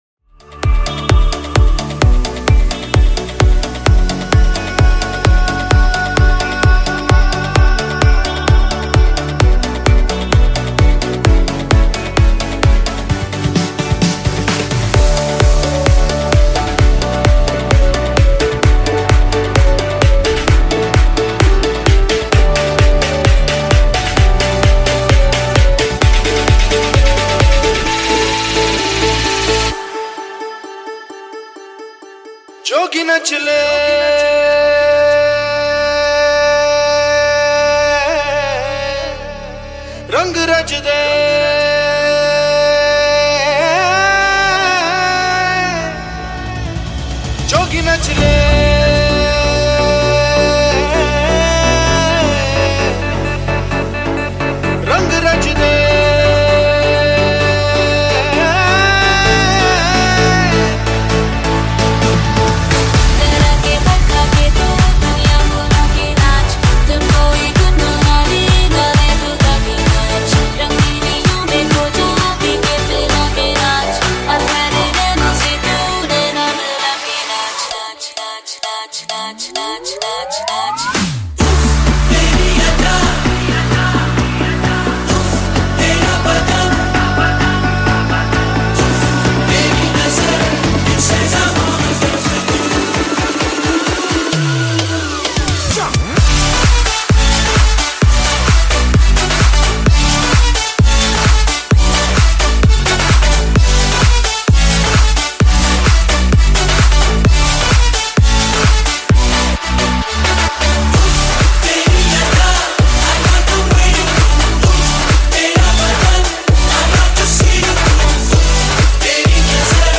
Bollywood Dance Club Mix